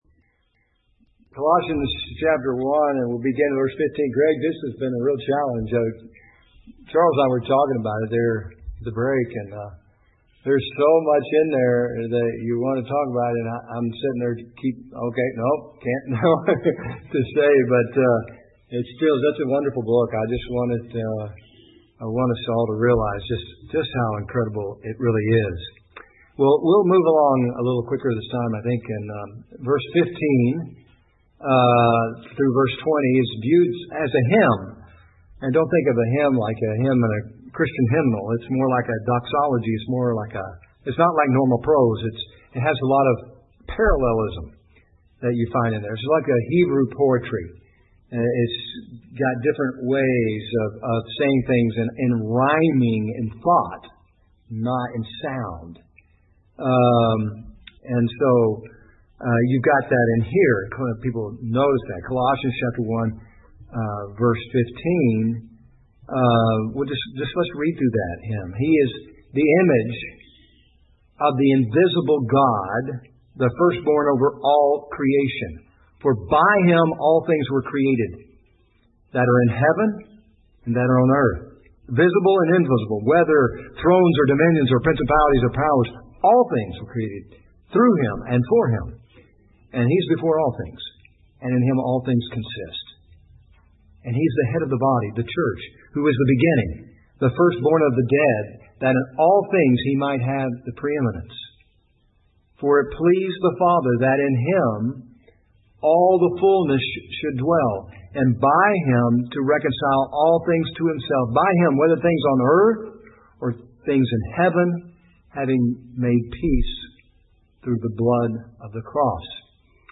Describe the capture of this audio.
Session 2 of the conference, looking at the person of Christ (His deity) and the work of Christ (reconciliation).